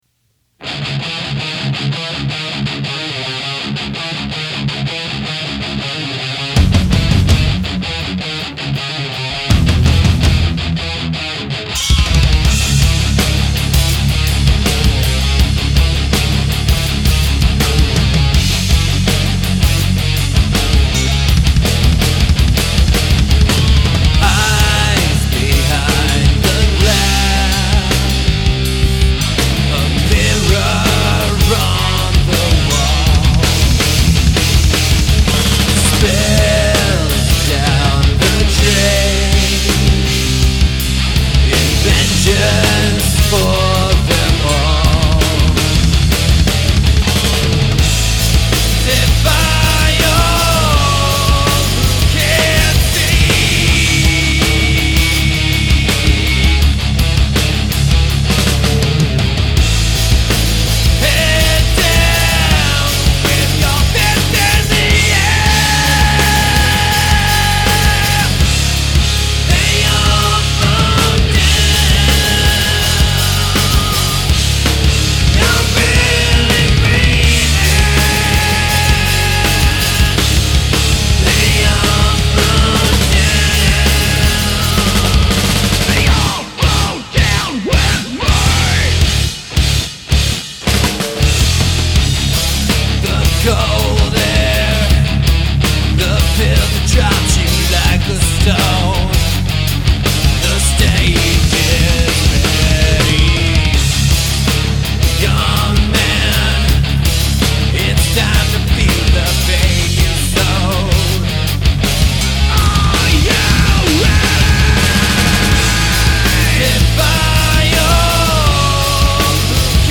It was just the guitar right into the amp.